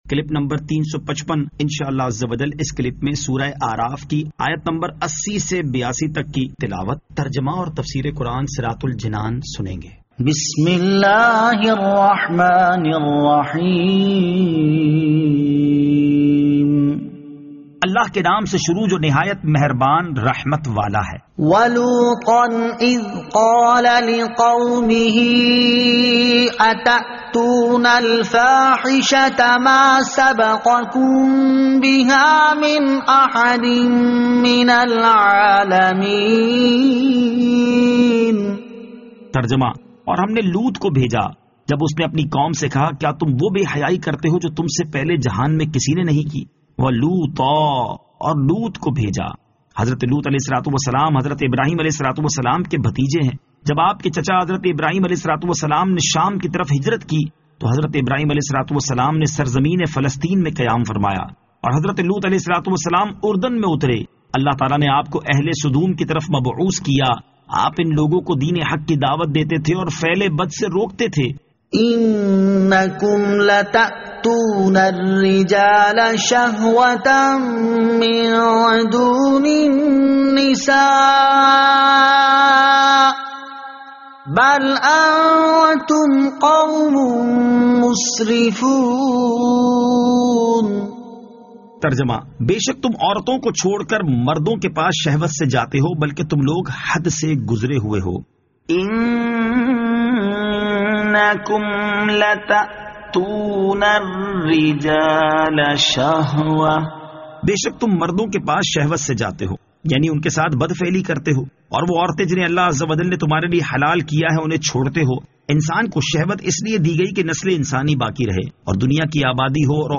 Surah Al-A'raf Ayat 80 To 82 Tilawat , Tarjama , Tafseer